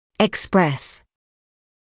[ɪk’spres][ик’спрэс]выражать (себя), проявлять (себя); реализовываться, отрабатывать (напр. о торке)